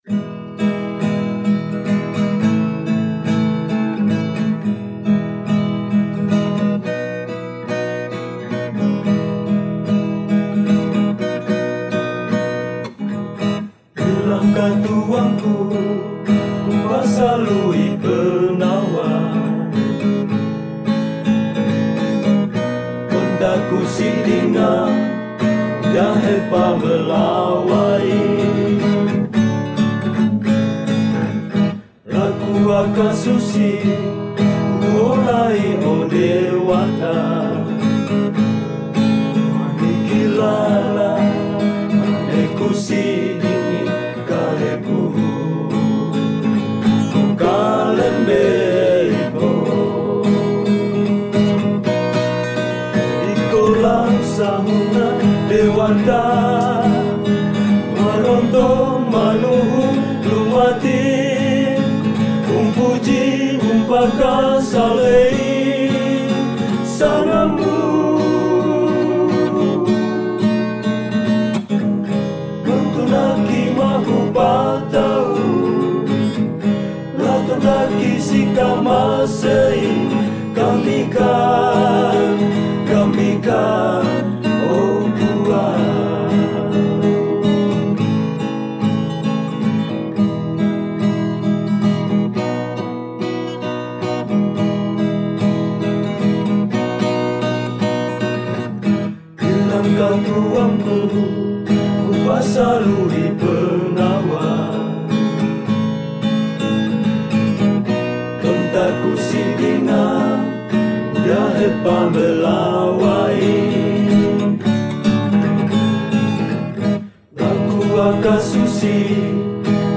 Lagu daerah bahasa bambam, dinyanyikan oleh masyarakat dari berbagai jemaat yang ada di wilayah Bambam.